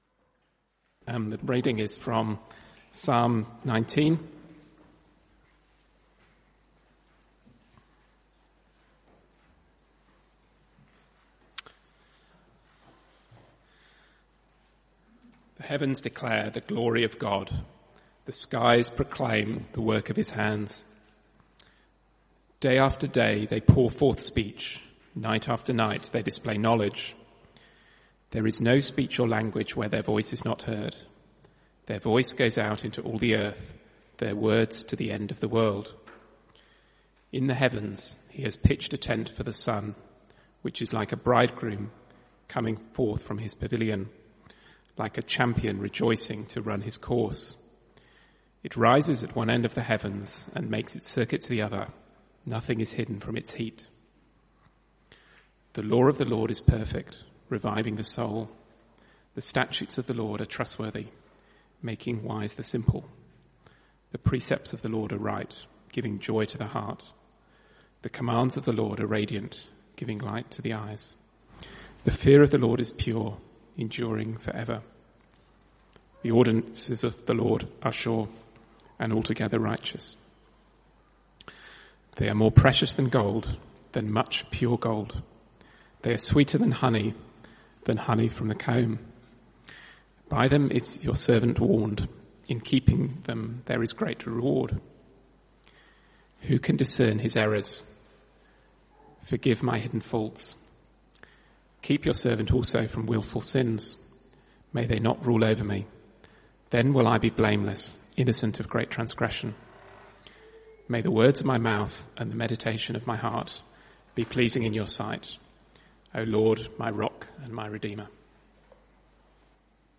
Psalm 19 Type: Sermons CBC Service